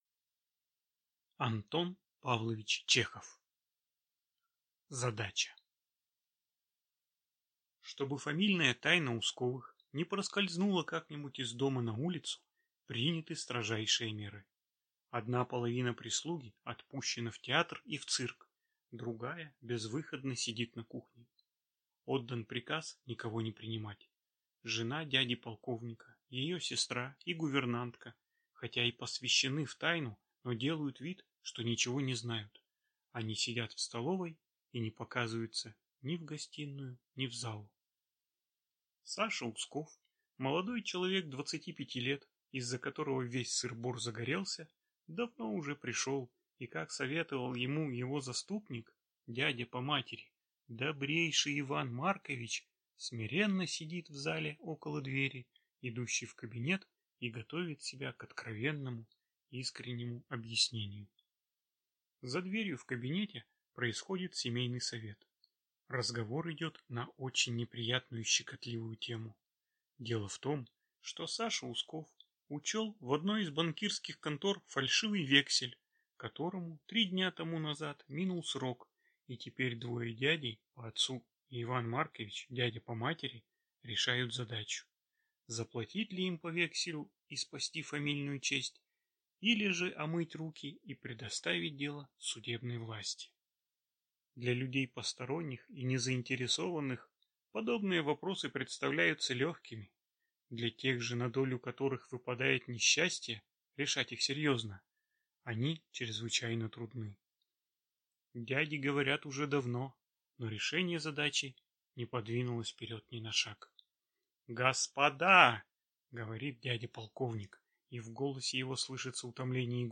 Аудиокнига Задача | Библиотека аудиокниг